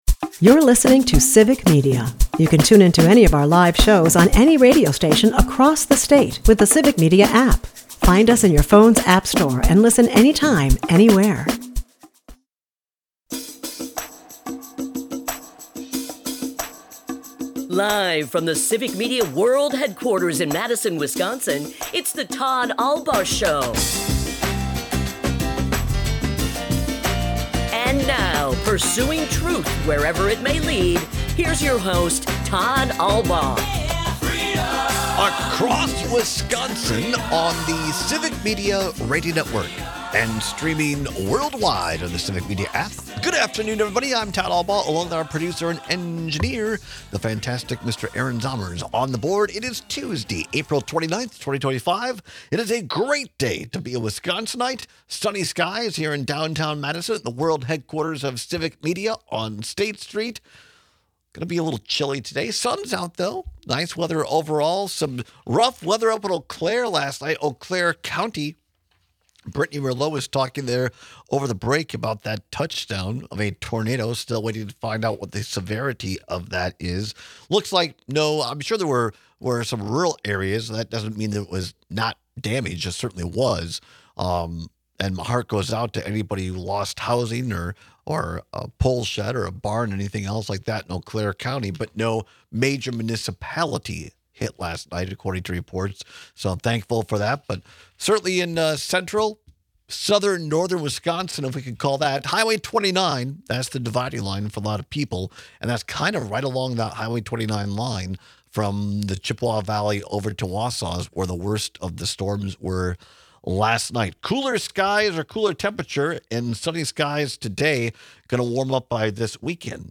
To kick off our second hour, we take more calls and texts on how the first 100 days of Trump’s second term have affected our listeners personally.